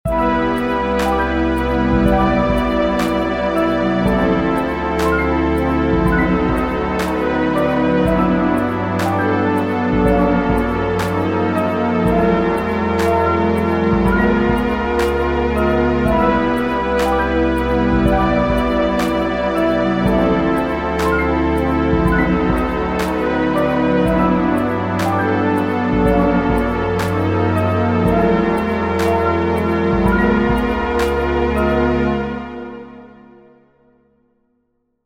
Short 120bpm loop in 19edo
19edo_demo.mp3